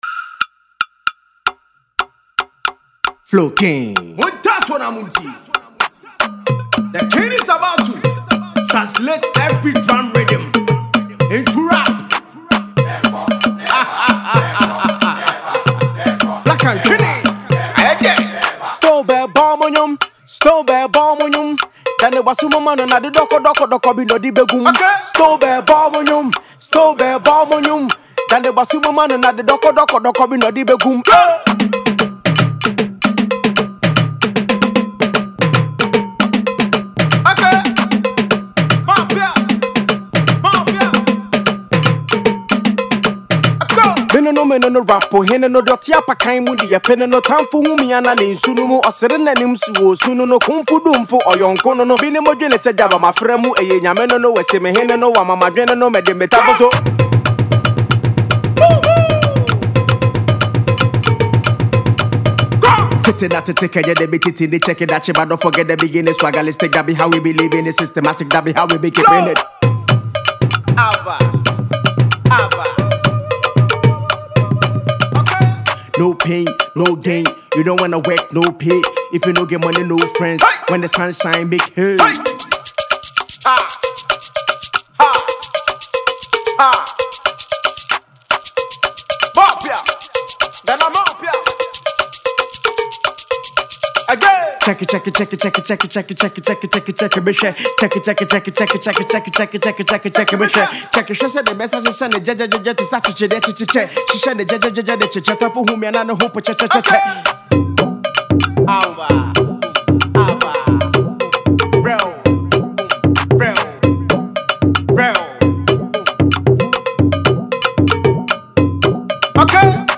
rapper